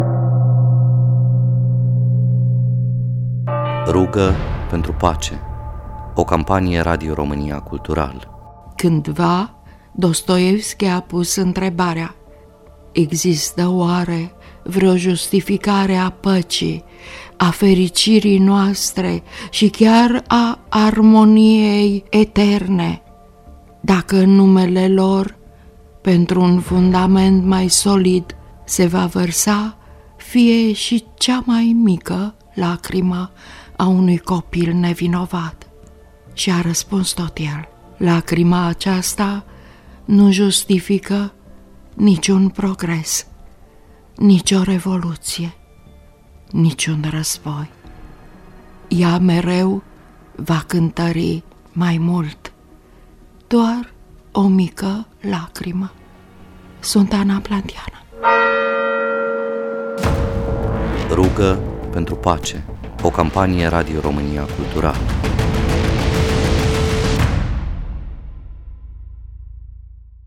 Numeroase teatre și instituții culturale din întreaga țară se alătură campaniei Radio România Cultural – RUGĂ PENTRU PACE – prin difuzarea unui fragment literar cu un mare impact emoțional, în lectura poetei Ana Blandiana, prin care postul public de radio face un un apel la solidaritate, empatie si compasiune față de tragedia razboiului din Ucraina.